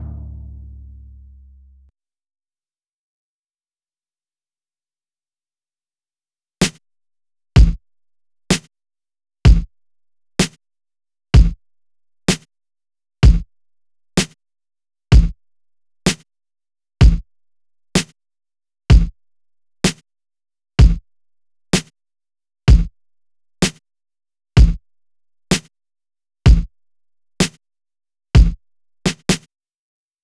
14 drums B2.wav